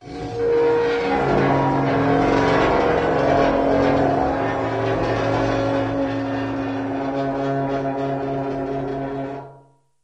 Metal Chalkboard Squeal Steady